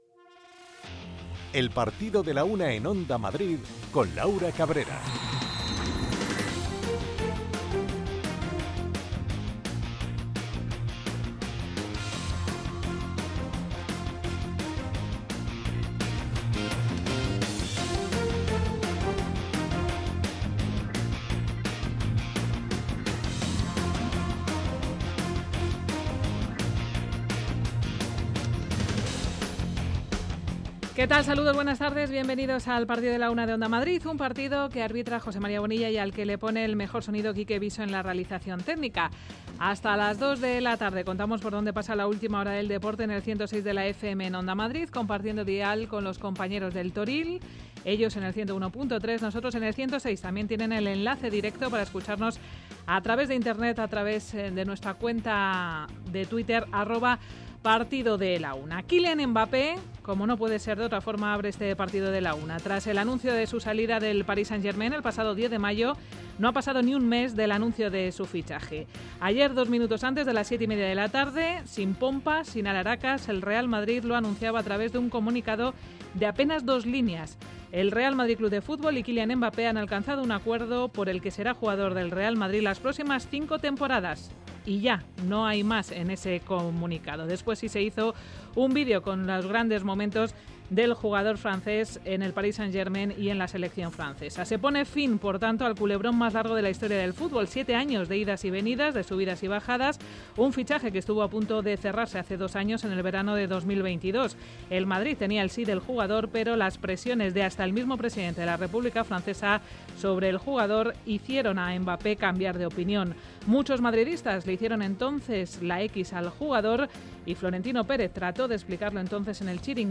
Entrevista a Jorge Miramón, jugador que también vivió el primer ascenso en 2016.